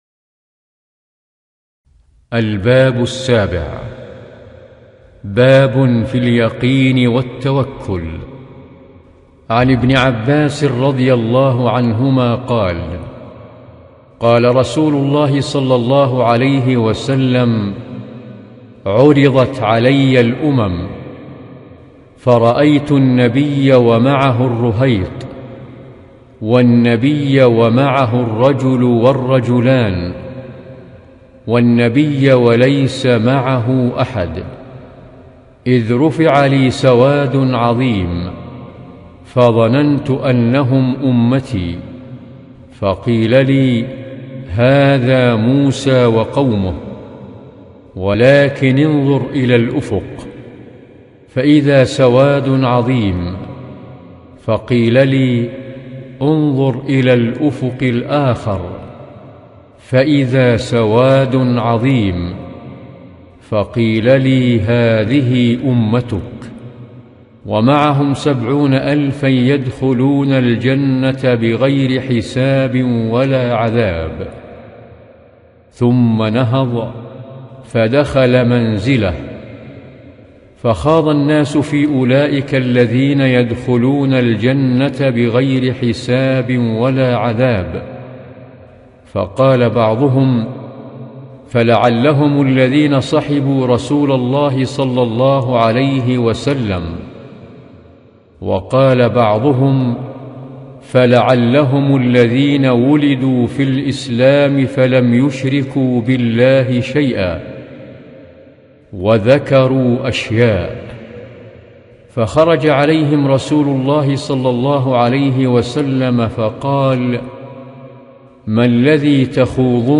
رياض الصالحين(باب اليقين والتوكل)...قراءة